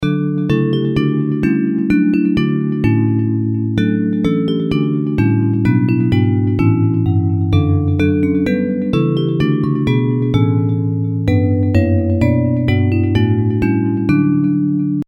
Hymns of praise
Bells